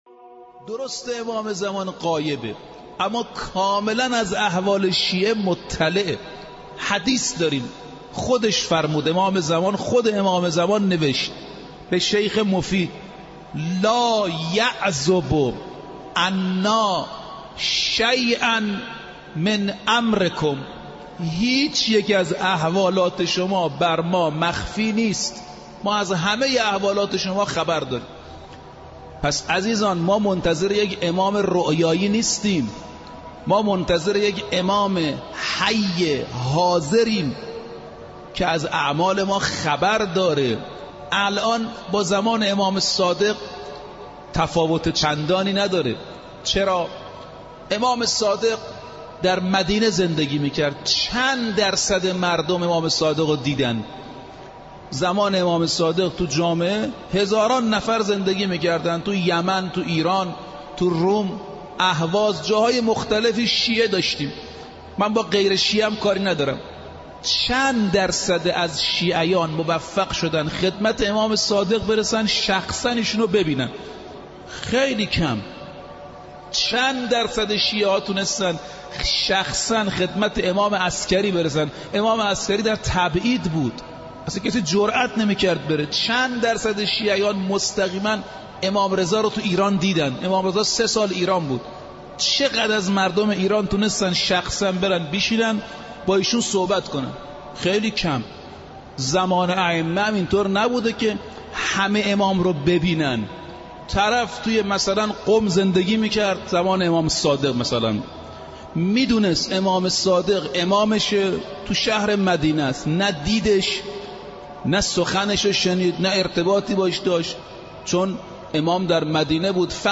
بخشی از سخنرانی